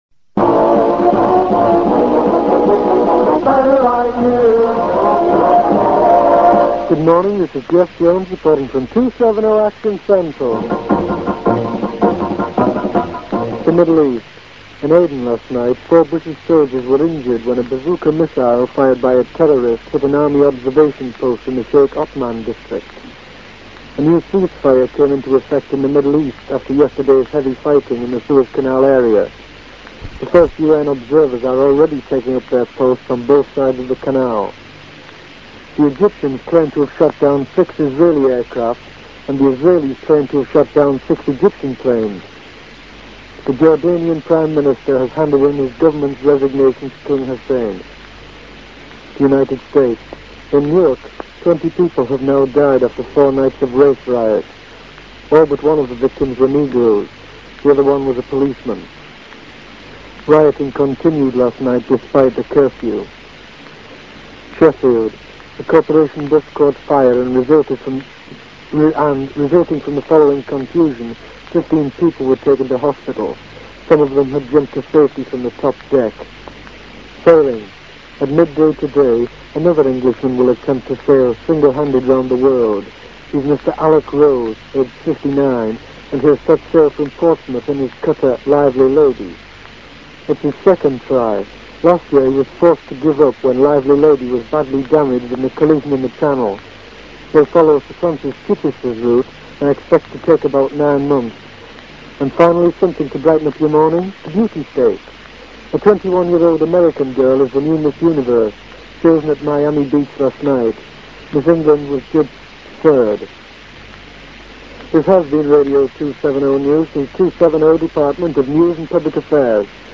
reading the 10am news on Radio 270, 6th July 1967